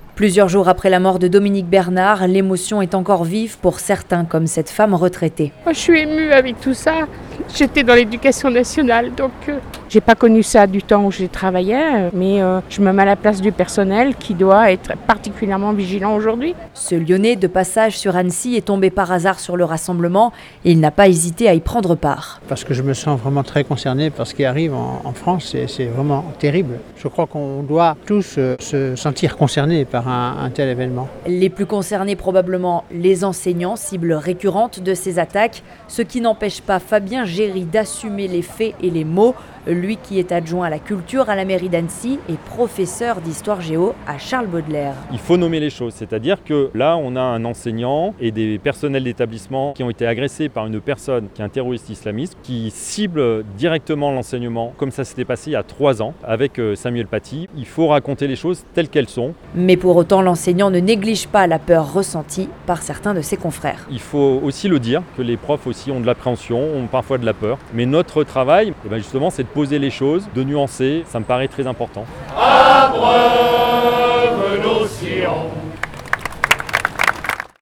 La mairie d’Annecy a organisé lundi 16 octobre 2023 un rassemblement d’hommage à Dominique Bernard, assassiné vendredi 13 octobre dans son collège d’Arras.